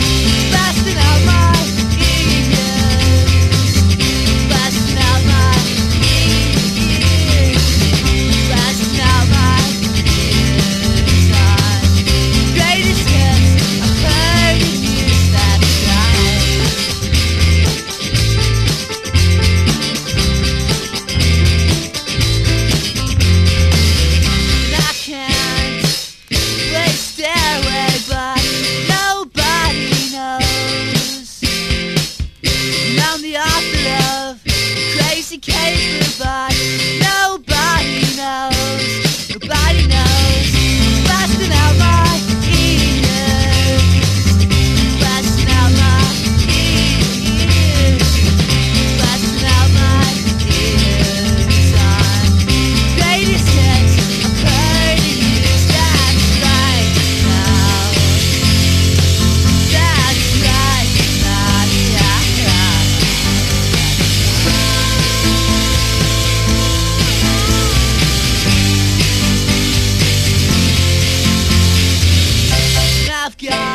清涼感あふれるジャングリー・インディ・ポップ良作！